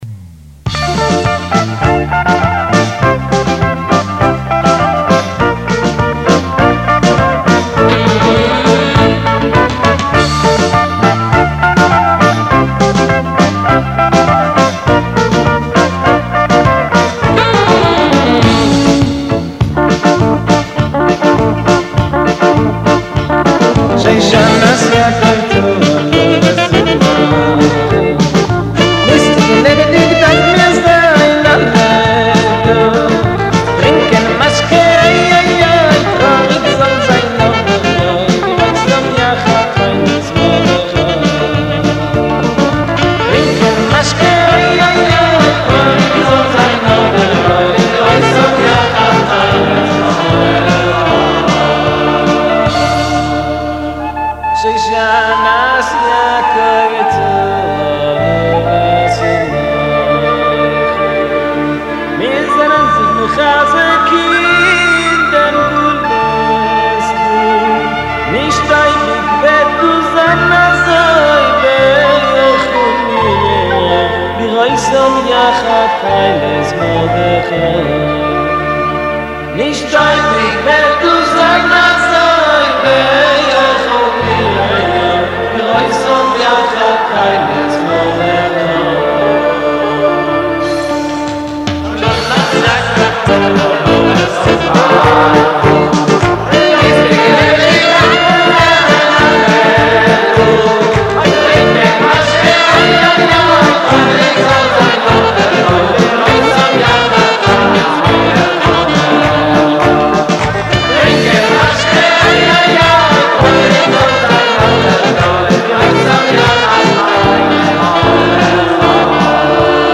גראמען על שושנת יעקב עם תוכן מאוד יפה, הביצוע... איך לומר.. קצת חובבני.